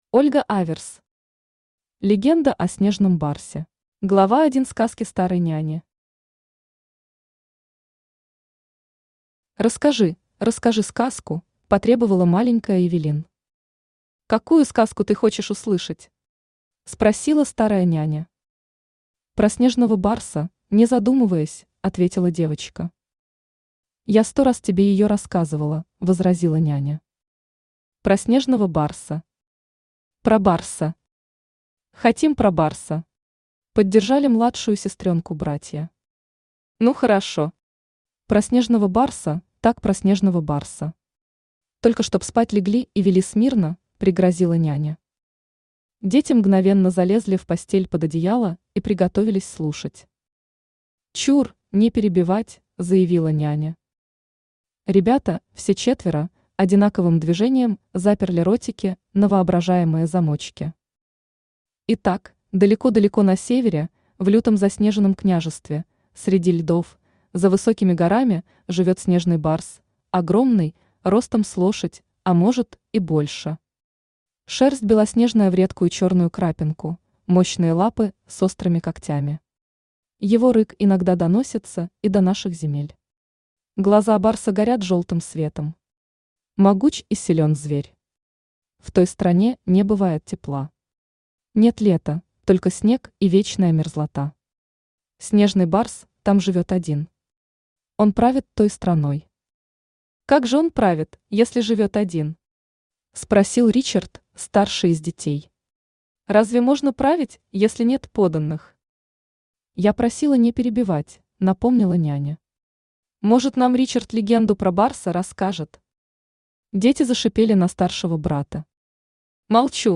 Aудиокнига Легенда о Снежном барсе Автор Ольга Викторовна Аверс Читает аудиокнигу Авточтец ЛитРес.